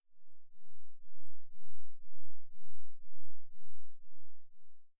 tone1hz.mp3